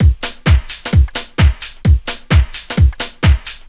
Drum Loops 130bpm